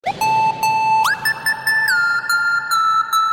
Sms Sound Effects ringtone free download
Message Tones